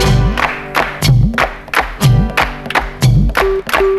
• 120 Bpm Drum Beat G Key.wav
Free breakbeat sample - kick tuned to the G note. Loudest frequency: 1172Hz
120-bpm-drum-beat-g-key-GVZ.wav